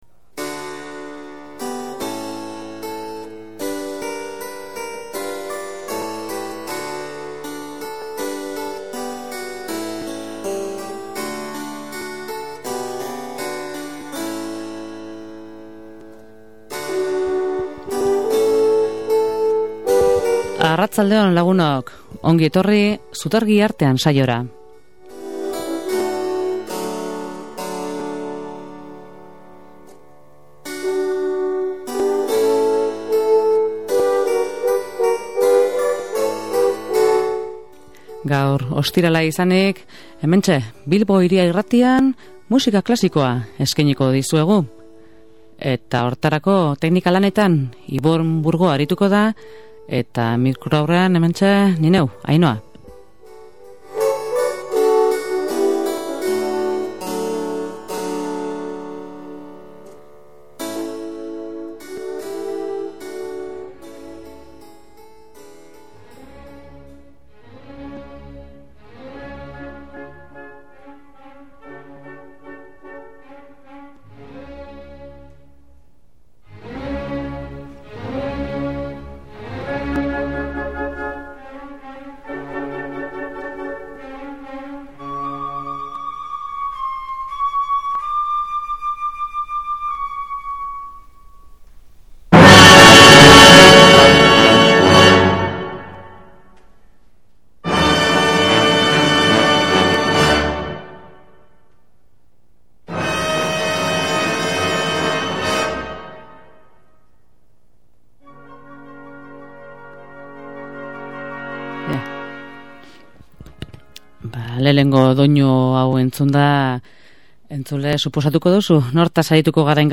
Horrez gain bere musika instrumentaletik aukeratutako pieza batzuk entzuteko aukera izango dugu.